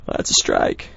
b_strike_1.wav